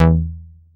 DOWN BASS E4.wav